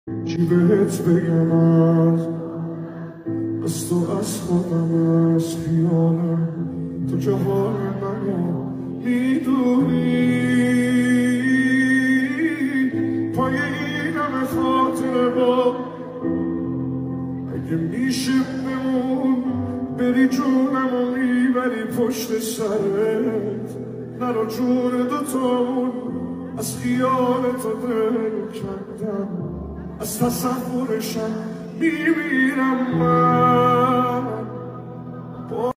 • النوع الموسيقي: بوب فارسي / موسيقى عاطفية
اللحن الهادئ والمرهف